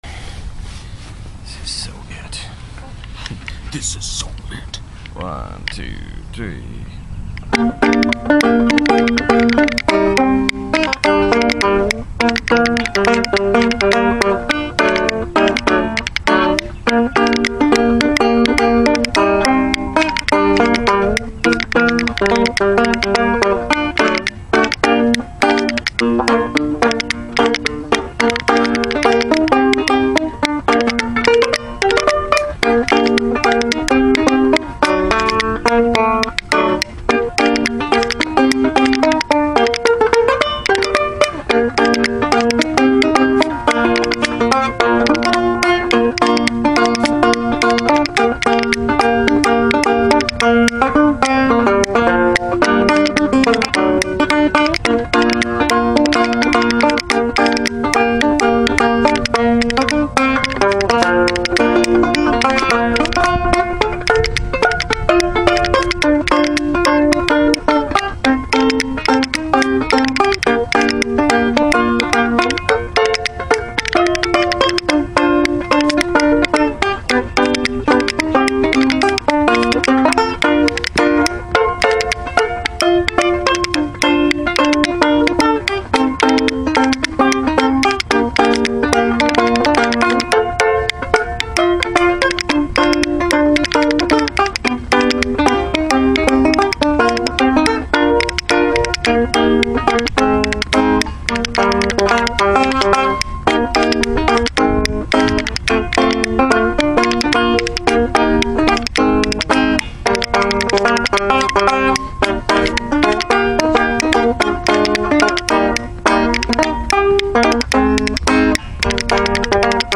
banjo songs